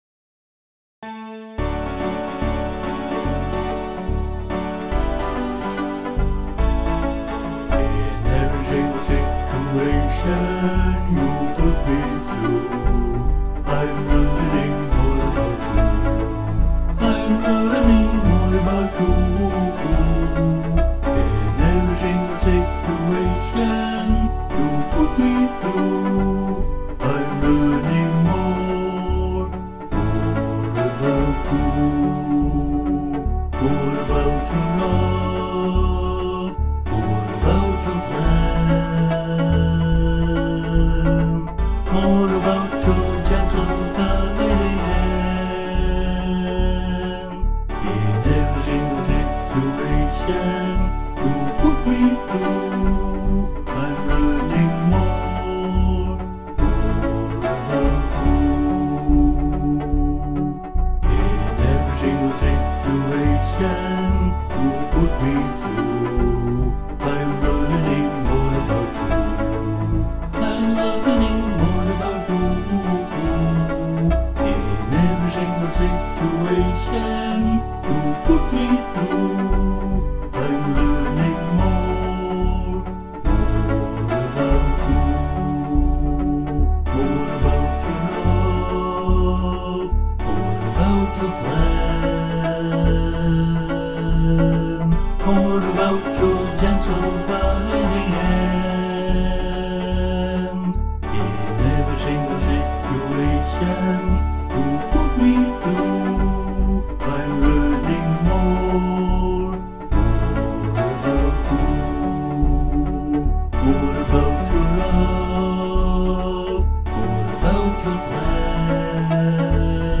Joyfully, in a country style